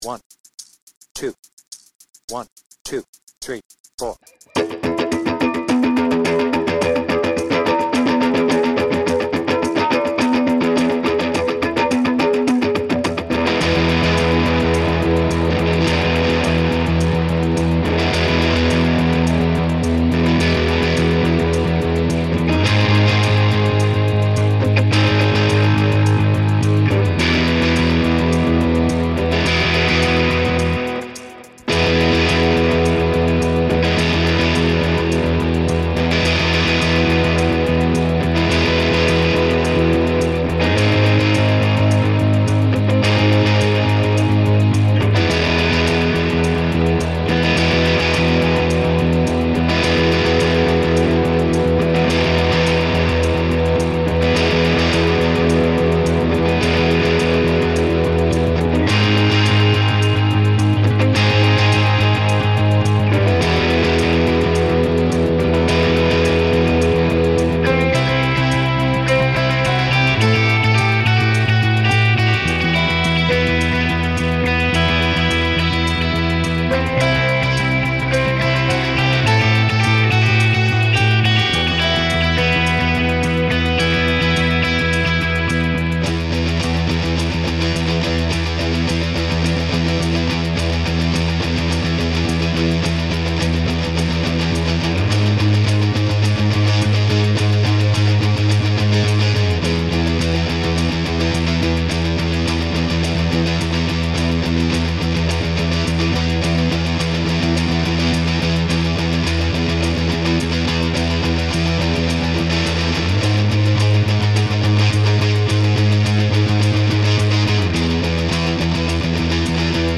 BPM : 106
Tuning : E
With vocals
Based on 360° Tour and album